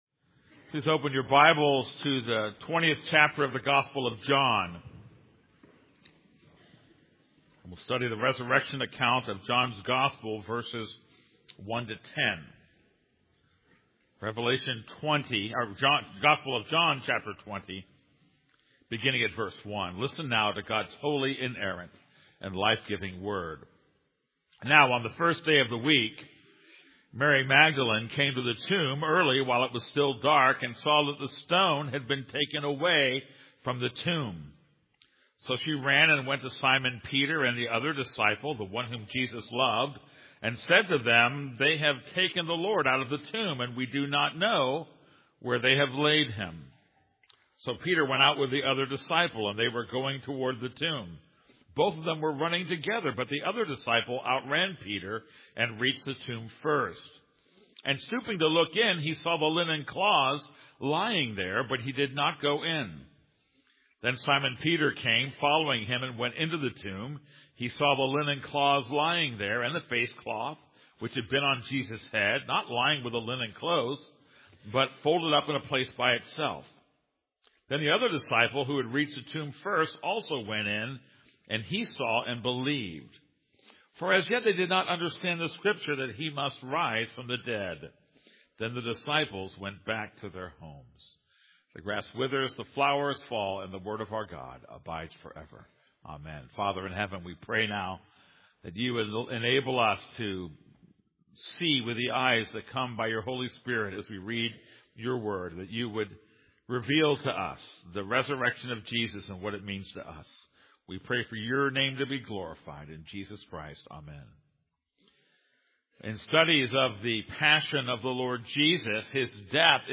This is a sermon on John 20:1-10.